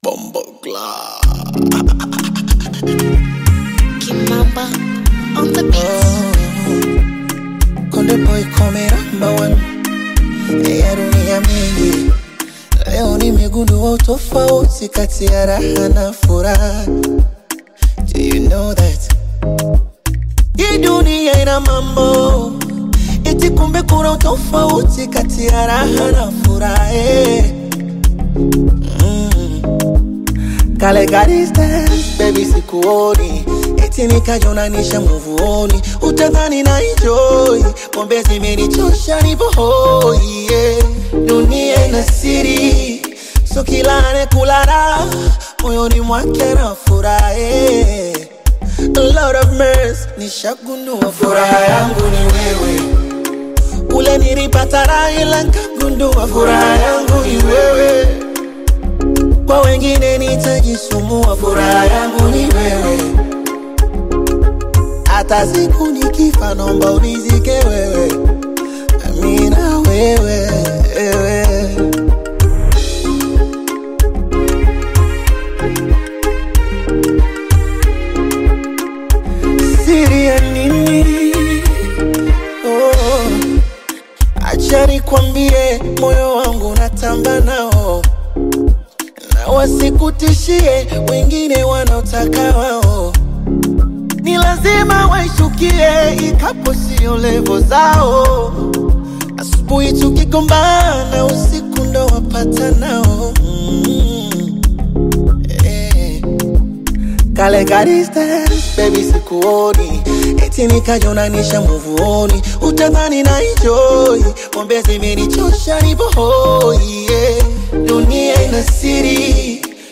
is a vibrant and soulful single
Genre: Bongo Flava